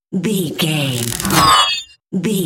Sci fi appear whoosh
Sound Effects
futuristic
high tech
whoosh